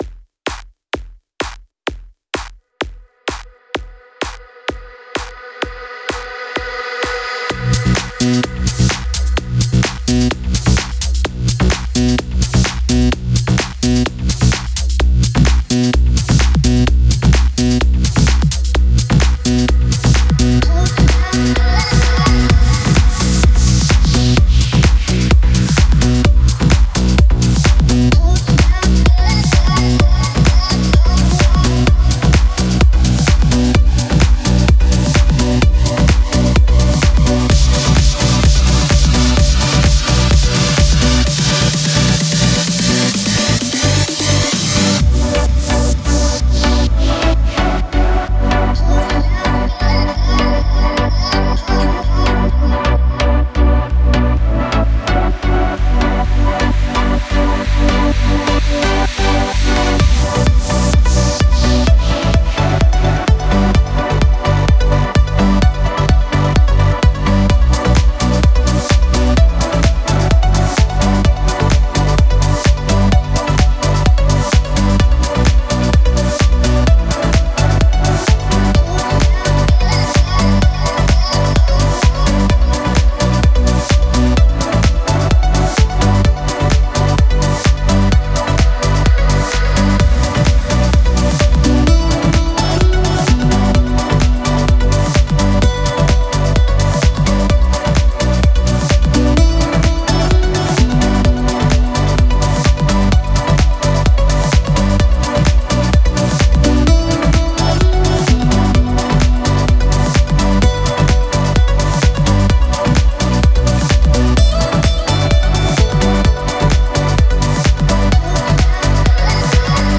Стиль: Progressive House / Melodic Progressive